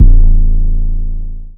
808 (Splashin).wav